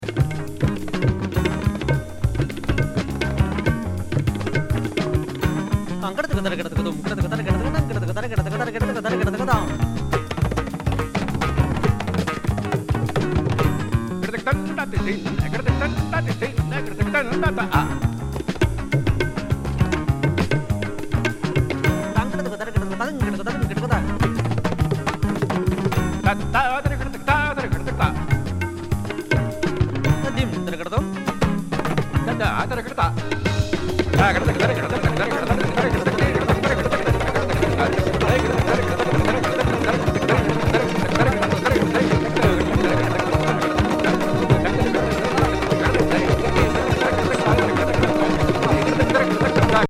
インド出身のお医者さん兼ヴァイオリン奏者のUSフュージョン・ミーツ・東洋神秘
タブラとホニャララ声の超高速バトル